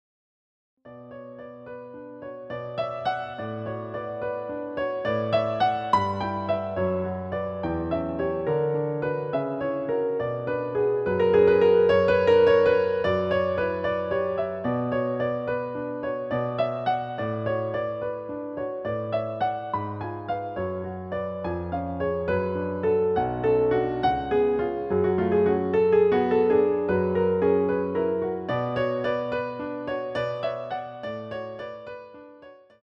using the stereo sa1mpled sound of a Yamaha Grand Piano.